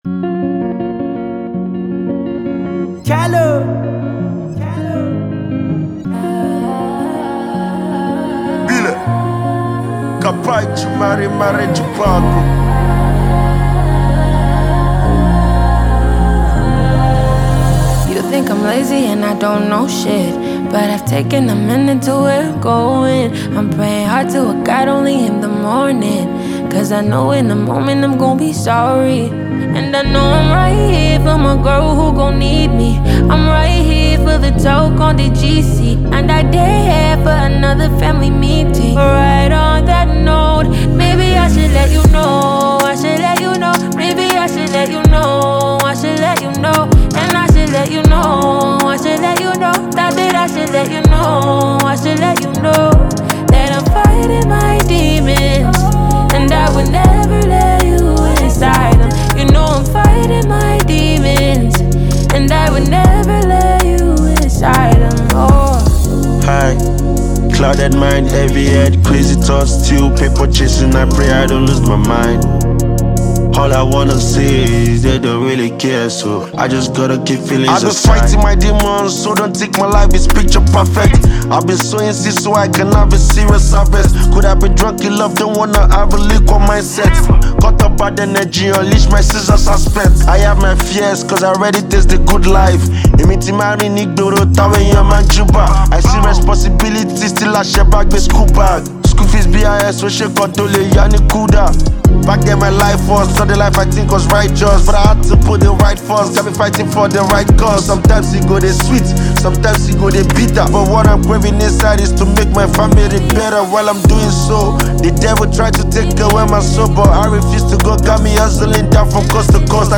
Renowned Nigerian Afrobeats talent and performer
The music scene is excited to embrace this energetic release